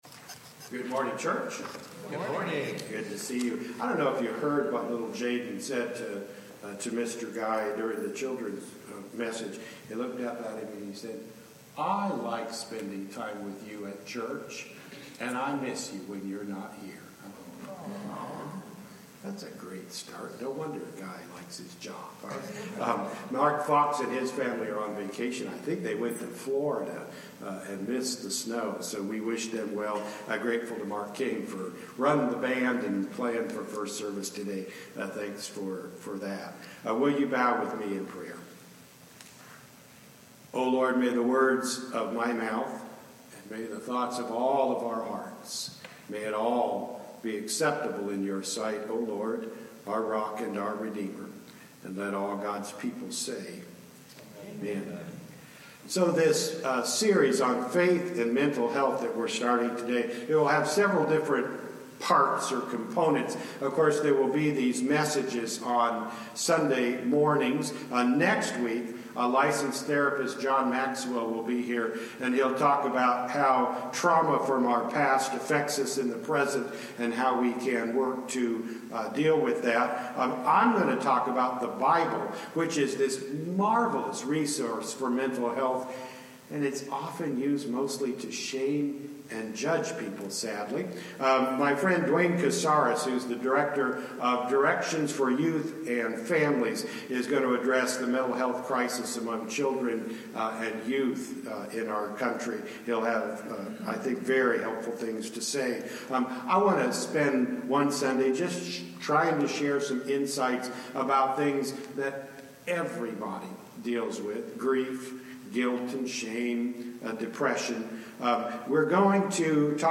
Contemporary Worship Service Replays | Bethel International United Methodist Church
The sermon begins at the 23:00 minute marker.